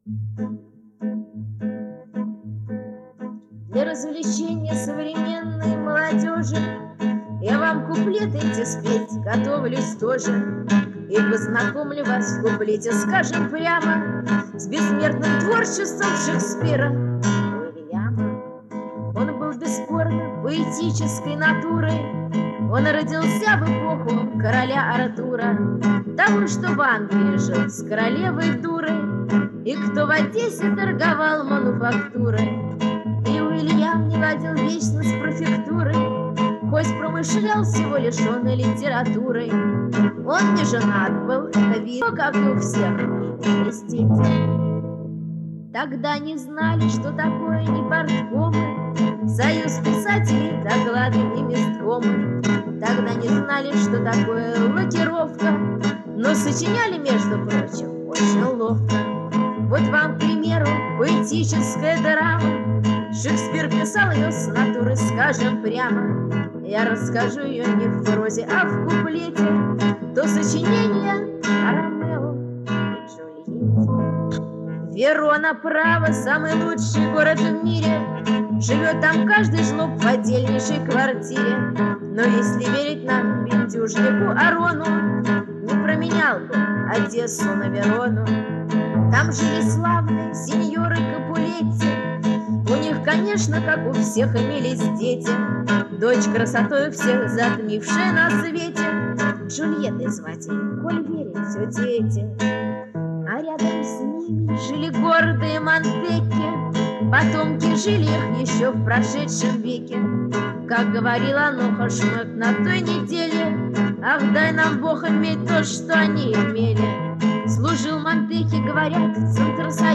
Аккомпанирует себе на гитаре
зачем было заполнять её какой-то дешевкой в стиле самодеятельности шестидесятых ?